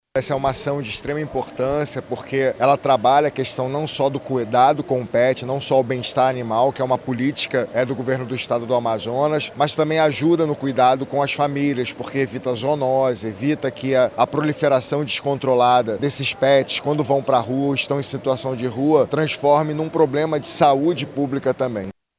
O secretário de Meio Ambiente do Amazonas, Eduardo Taveira, ressalta a importância do serviço.